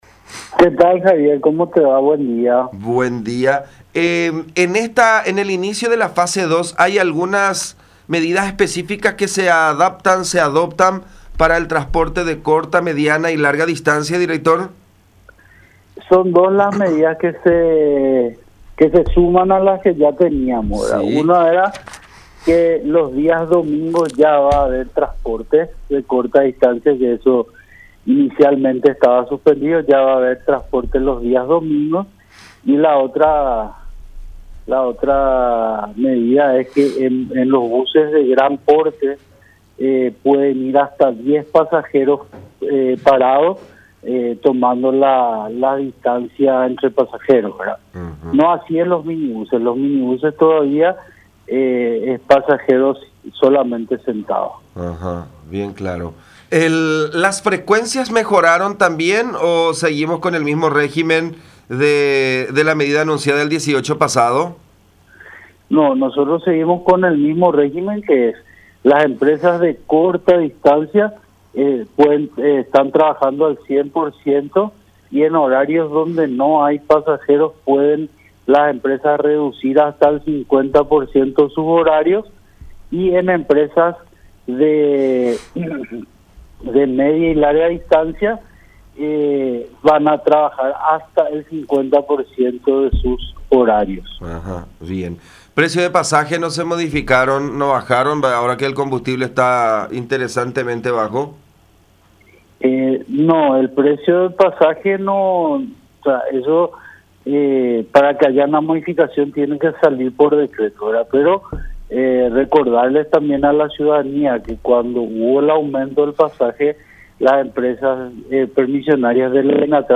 “En los buses de gran porte podrán circular hasta 10 pasajeros parados tomando las distancias”, dijo Juan José Vidal, titular de DINATRAN, en diálogo con La Unión.